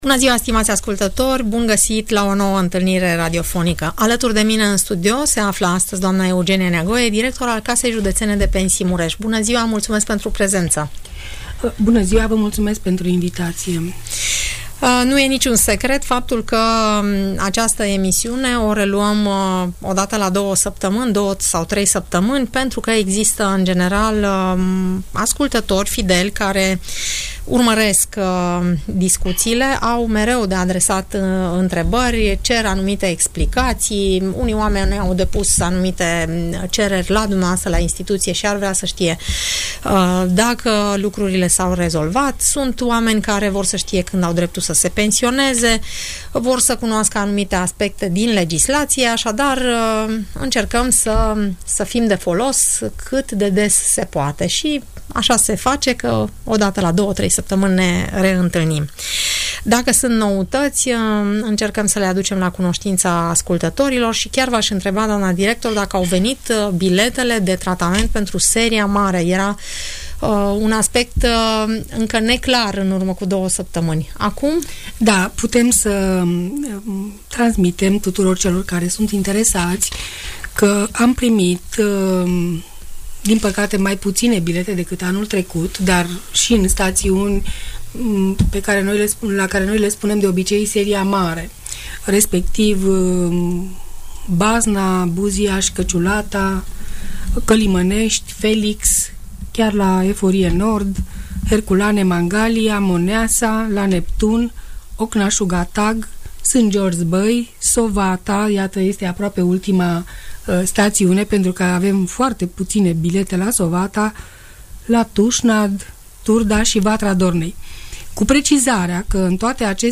Audiență radio cu întrebări și răspunsuri despre toate tipurile de pensii, în emisiunea „Părerea ta” de la Radio Tg Mureș.
Audiență radio la Casa de Pensii Mureș